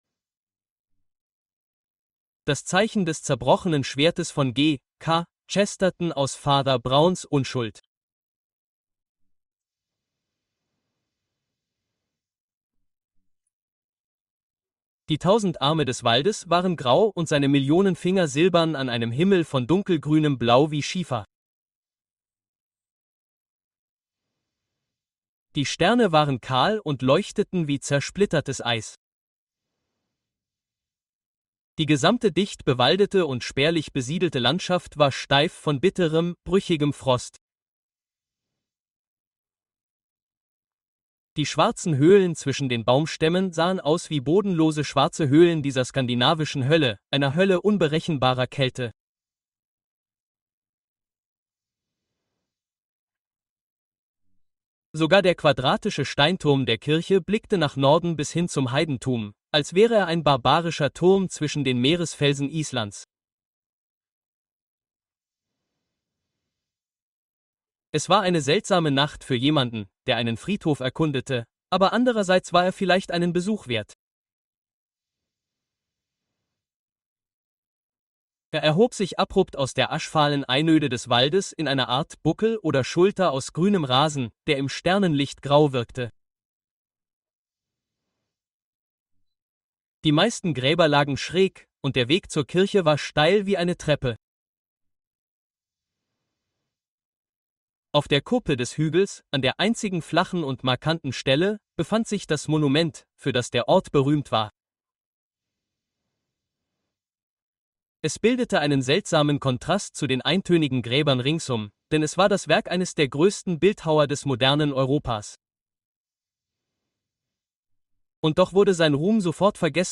The Crooked Man: Sherlock Holmes Uncovers Shocking Crime (Audiobook)